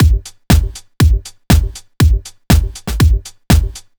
120 Minimal Freak Full.wav